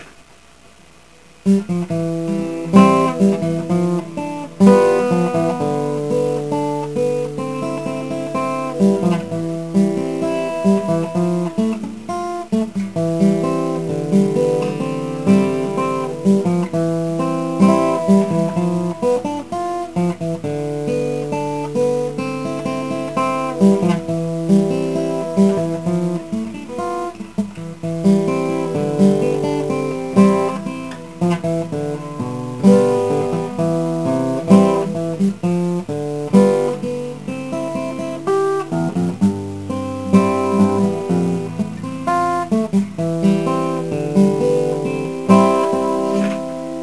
It's a little tricky, though, and the sound quality is very poor as a tradeoff for smaller files.
So you can count on lots of mistakes and hesitation, but the recording is so bad you may not even notice it.
It would be to the advantage of all of you who like amateurish buzzy-sounding silly songs recorded on a little computer microphone, and to the detriment of my disk quota on the CS web server, but goodness, there's a lot of songs I could play.